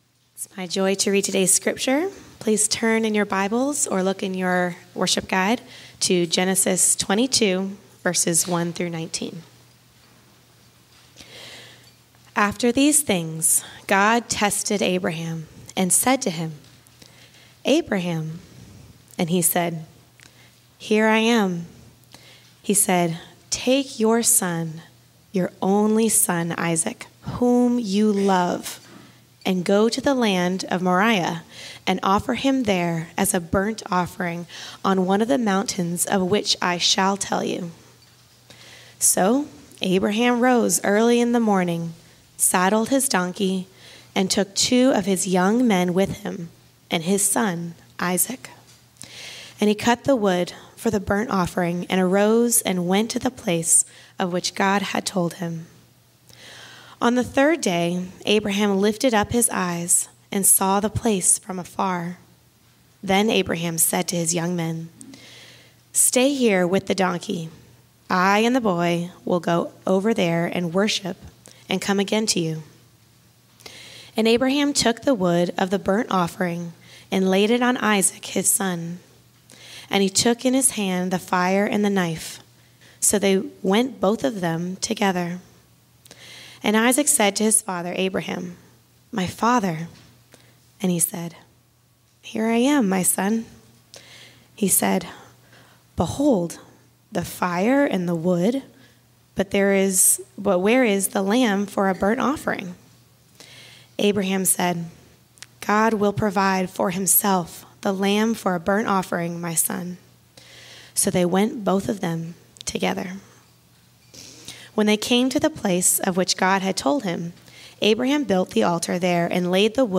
Format: Sermons